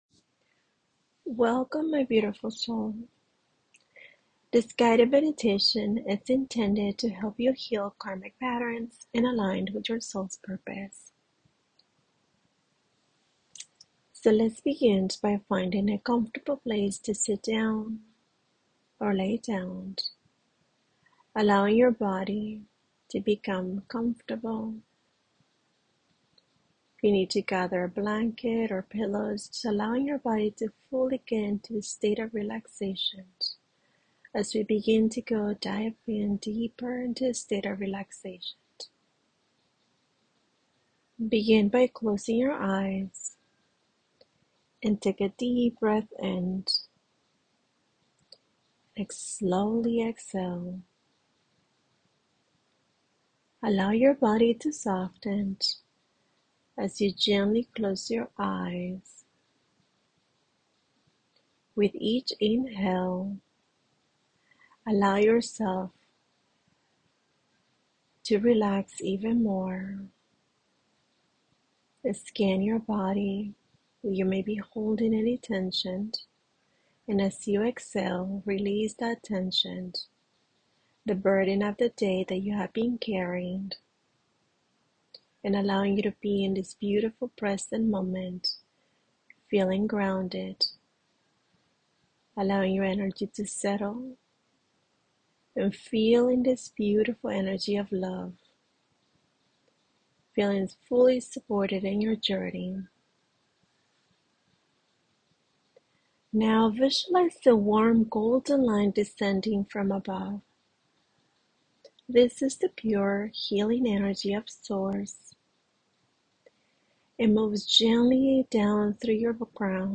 Guided-Meditation-Healing-Karmic-Patterns.mp3